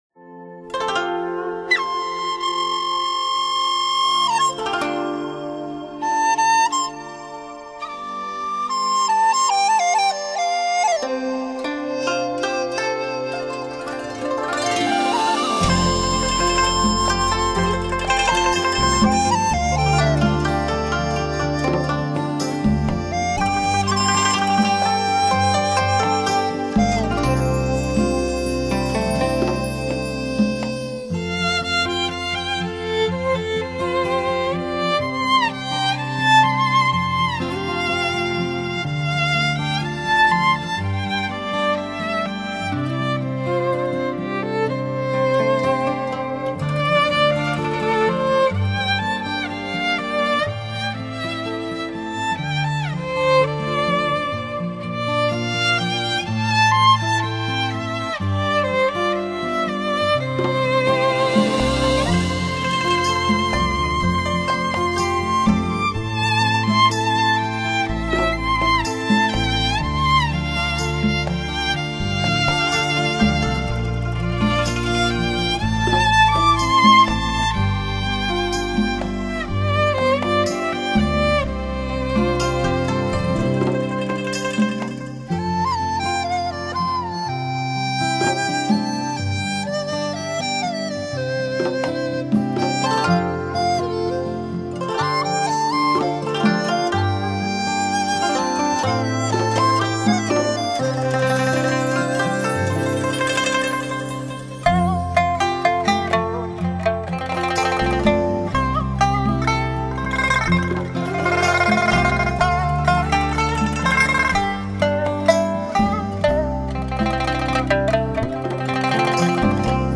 HI-FI典范，如歌旋律, 完美演奏 , 完美的录音浑然天成 . 质朴、自然、真实的录音， 音域宽广，
却又细腻绵绵， 音质清纯之美令人陶醉， 营造出一个充满活力的HIFI场面， 每一个音符都浸透感情和关切，
每一乐句都奏出串串温暖的感动， 音色的清晰厚度极为像真， 令中外发烧友赞誉不绝。